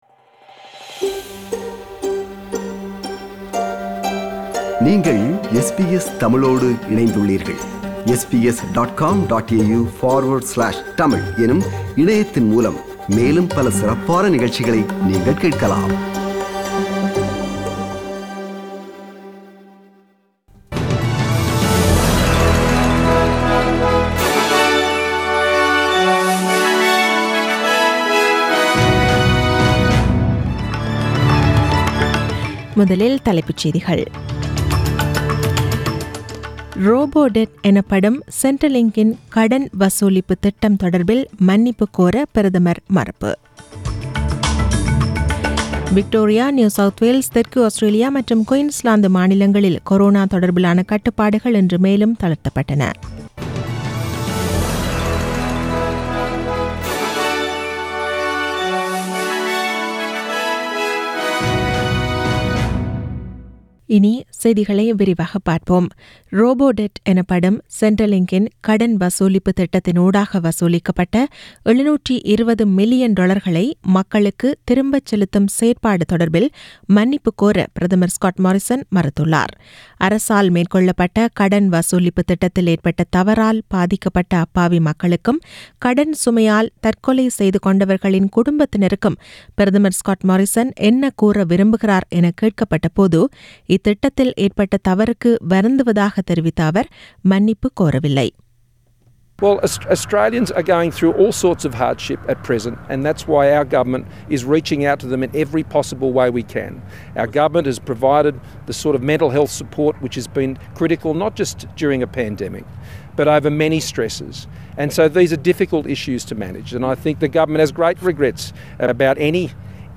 The news bulletin was aired on 01 June 2020 (Monday) at 8pm.